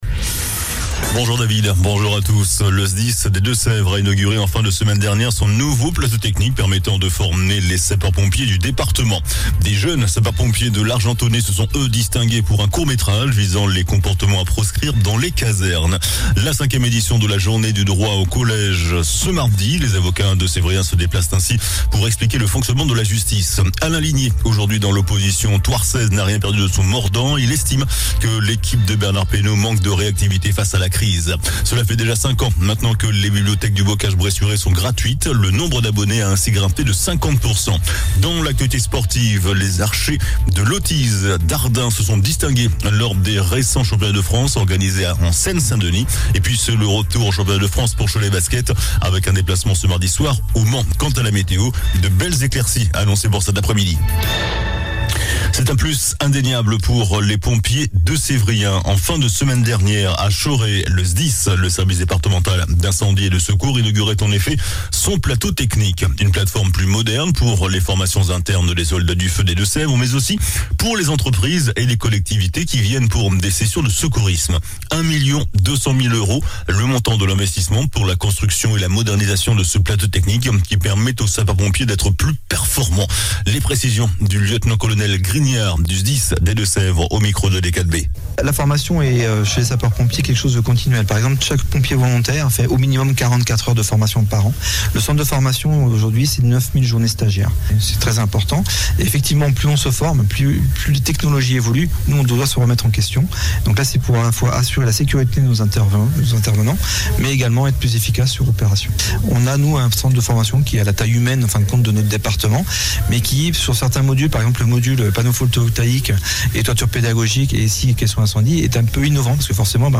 JOURNAL DU MARDI 04 OCTOBRE ( MIDI )